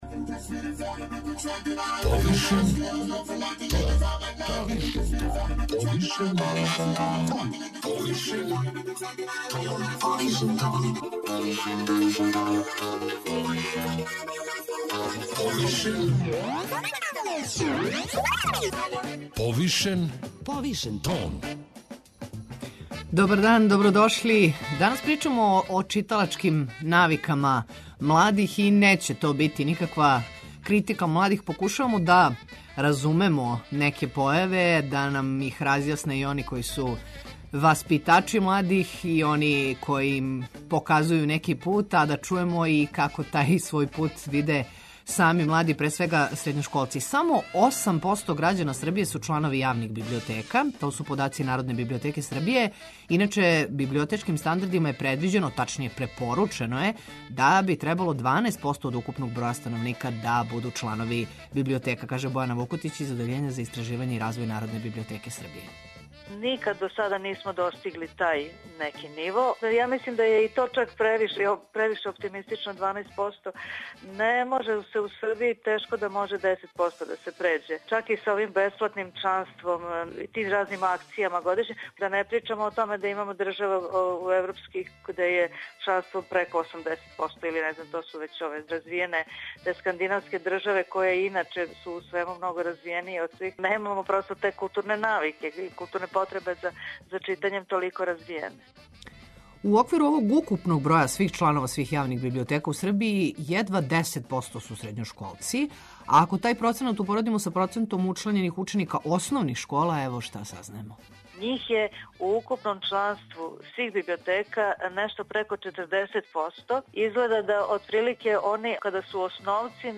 Скупштина општине Свилајнац, уводи за грађане картице које ће им обезбедити попусте и бесплатне програме у културним, научним и спортским установама. Какве ће све програме уз ове картице моћи да похађају деца, омладина и одрасли разговарамо са Предрагом Милановићем, председником општине Свилајнац у другом сату емисије.